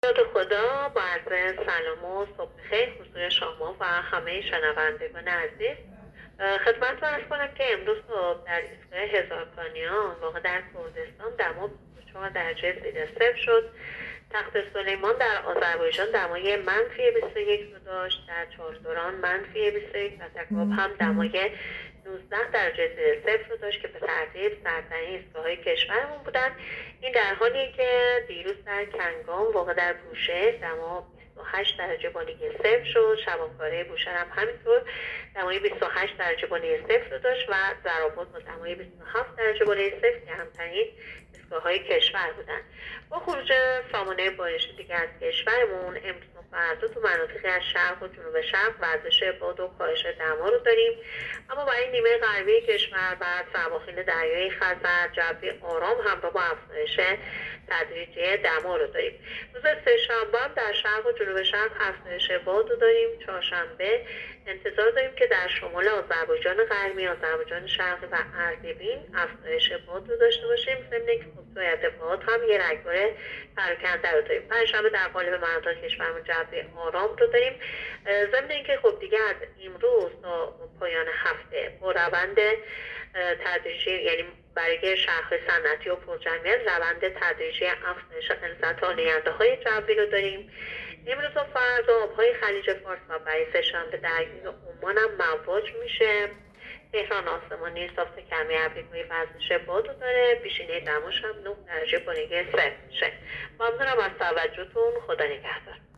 گزارش رادیو اینترنتی از آخرین وضعیت آب و هوای ۱۴ دی؛